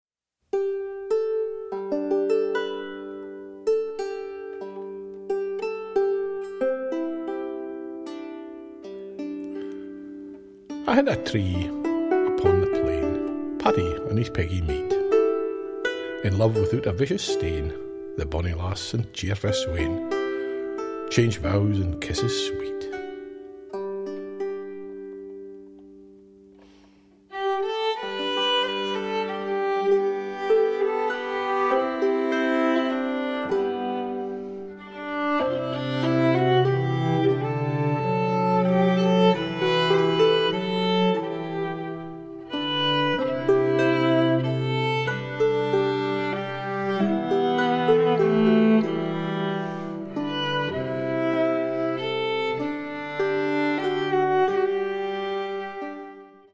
• Genres: Early Music, Opera